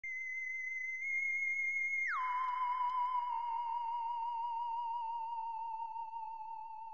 На этой странице собраны звуки, которые ассоциируются с инопланетянами и пришельцами: странные сигналы, электронные помехи, \
Звук инопланетян на звонок